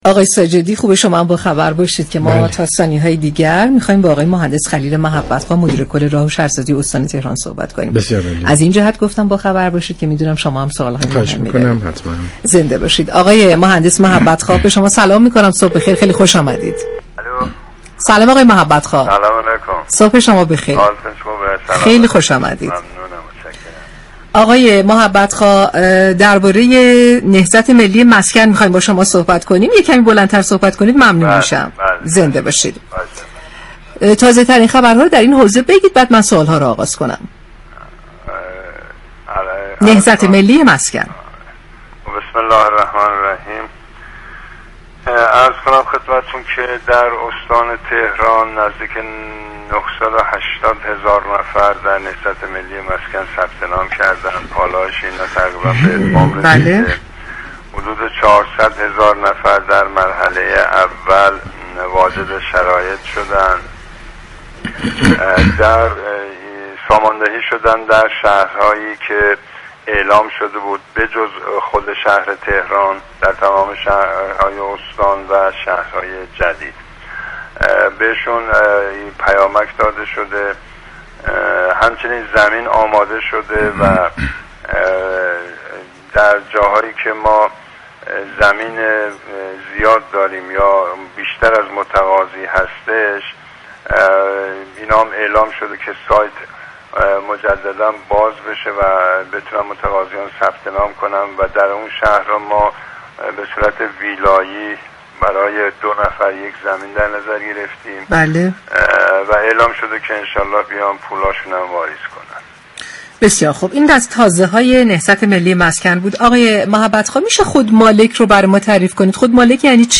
به گزارش پایگاه اطلاع رسانی رادیو تهران، خلیل محبت خواه مدیر كل راه و شهرسازی استان تهران در گفت‌و‌گو با شهر آفتاب رادیو تهران گفت: در استان تهران حدود 980 هزار نفر در طرح نهضت ملی مسكن ثبت نام كردند و حدود 400 هزار نفر در مرحله اول واجد شرایط شدند و در شهرهای جدید پرند، هشتگرد، پاكدشت، رباط كریم و ایوانكی ساماندهی شدند.